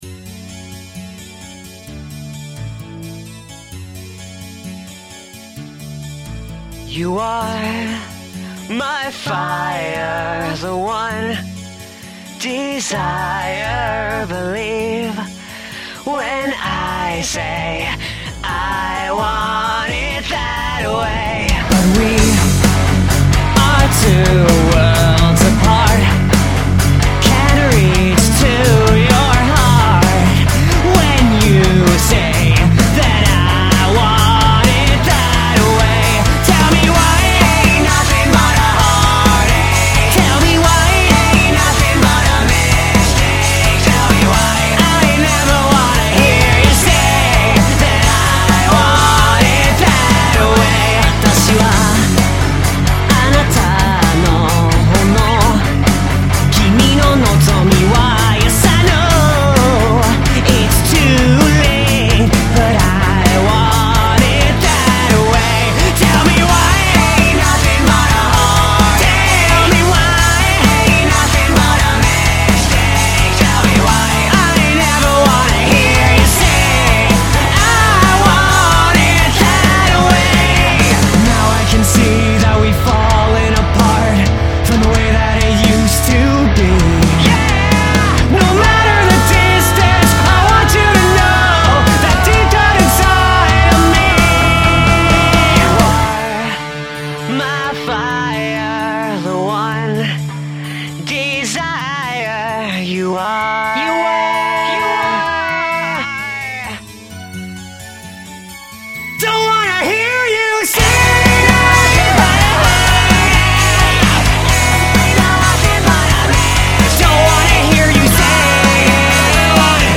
kawaii-ass punk cover